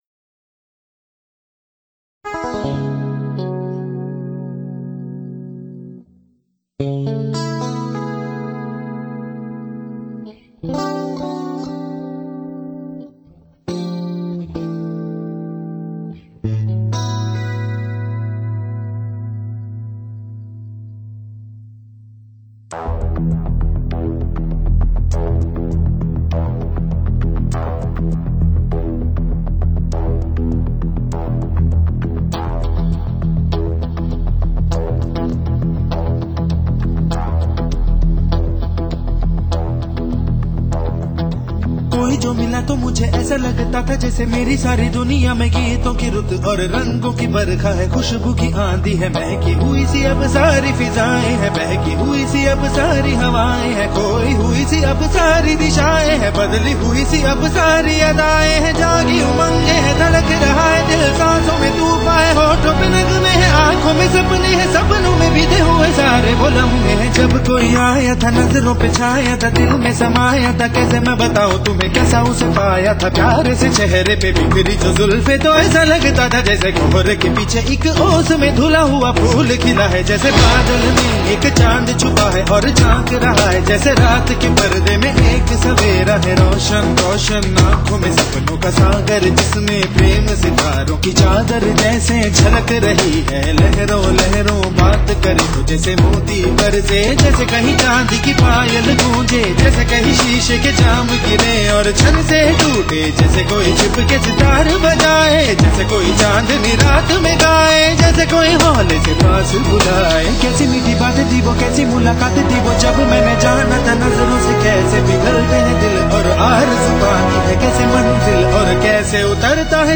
这是一首炫技之作。 歌名不是“没气了“,而是“不换气“， 他真的一口气唱了下来,近三分钟！
印度歌手是否用了换气技巧就不好说了，反正没听出来。